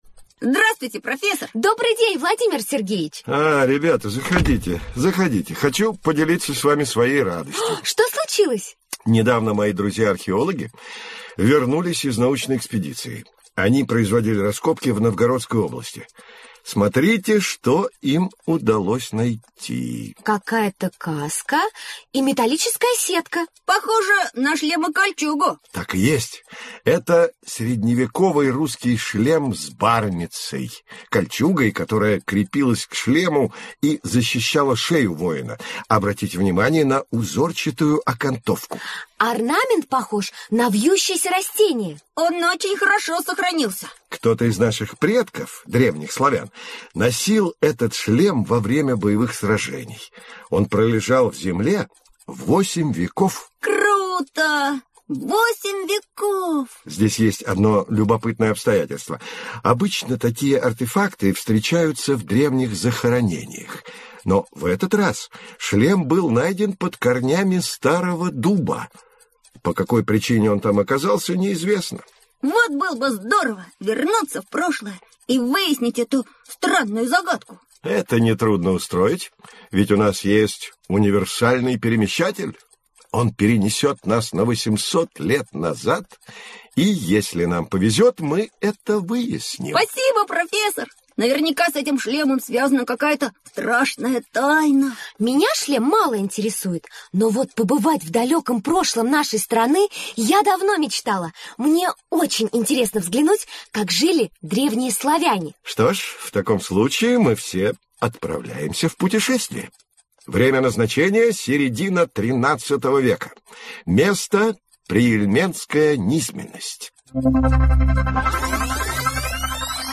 Аудиокнига История. Путешествие в древнюю Русь | Библиотека аудиокниг